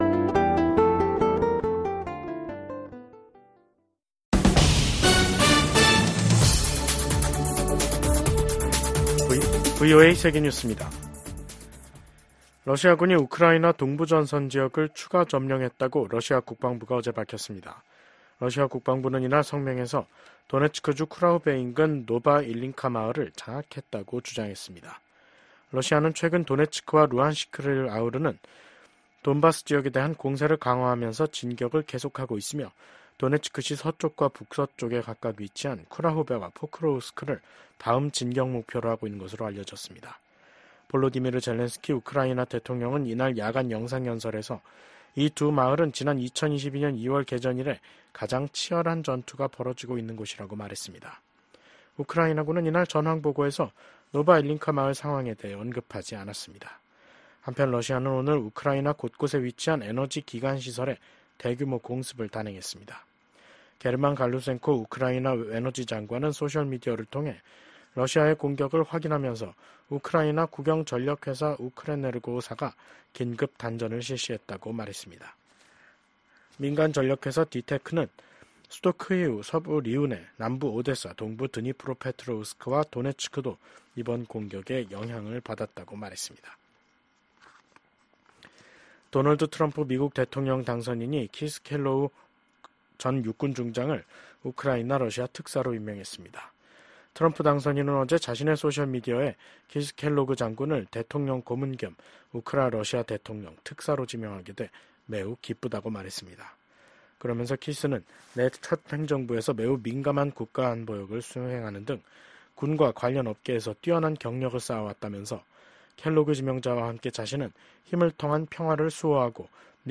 VOA 한국어 간판 뉴스 프로그램 '뉴스 투데이', 2024년 11월 28일 2부 방송입니다. 우크라이나 사태를 주제로 열린 유엔 안보리 회의에서 미국 대표는 북한을 향해 러시아 파병이 사실이냐고 단도직입적으로 물었고, 북한 대표는 북러 조약 의무를 충실히 이행하고 있다며 이를 우회적으로 시인했습니다. 러시아와 전쟁 중인 우크라이나 특사단이 윤석열 한국 대통령 등을 만났지만 한국 측은 무기 지원에 신중한 입장을 보였습니다.